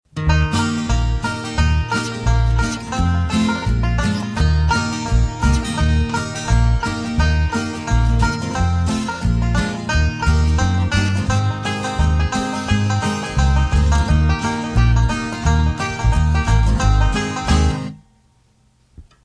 Children's Song About Frogs